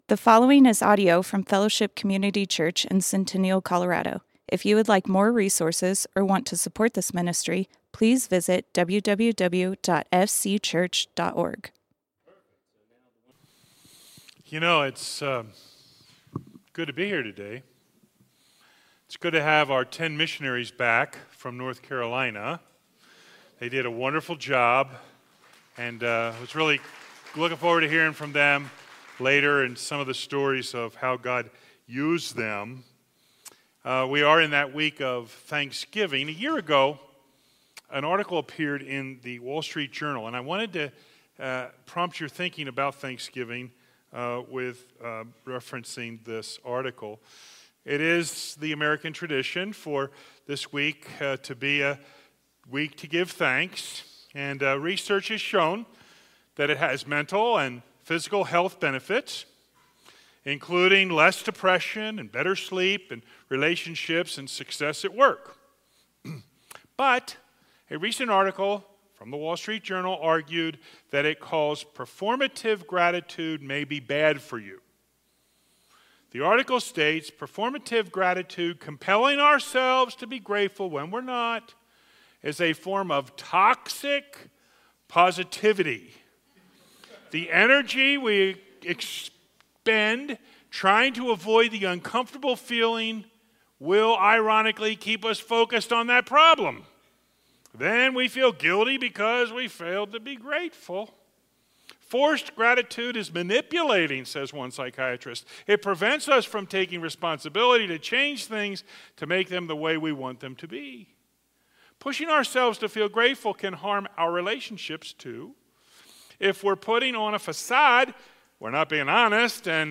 Fellowship Community Church - Sermons Jesus Giving Thanks Play Episode Pause Episode Mute/Unmute Episode Rewind 10 Seconds 1x Fast Forward 30 seconds 00:00 / 30:05 Subscribe Share RSS Feed Share Link Embed